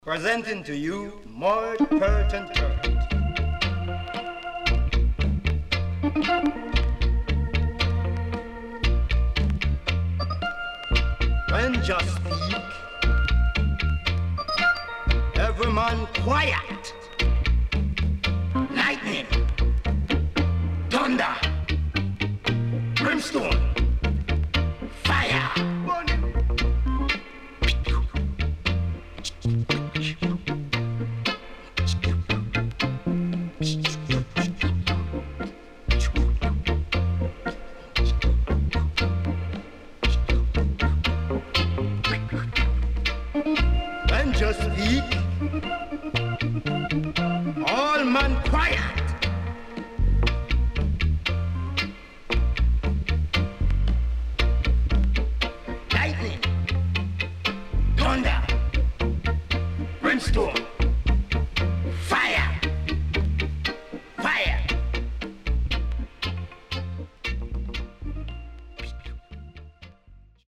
70's EARLY REGGAE
SIDE A:プレス起因によりかるいヒスノイズ、少しチリプチノイズ入ります。